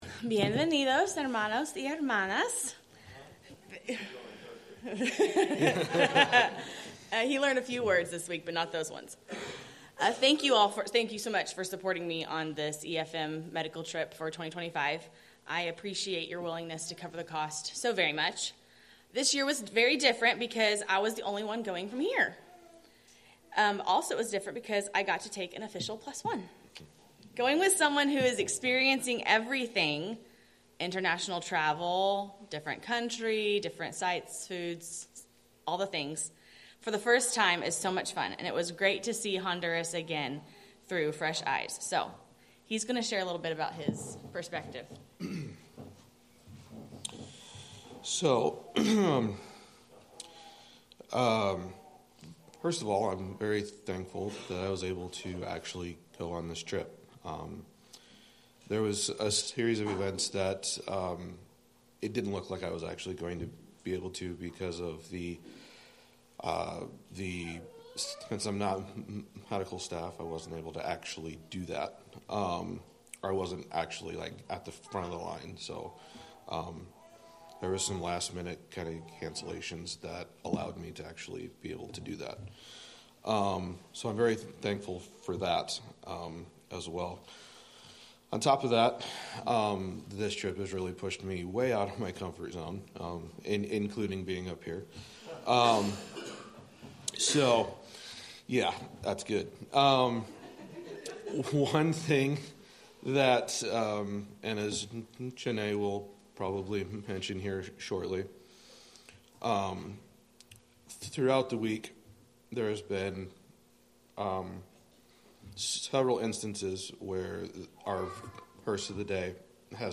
Medical Team Report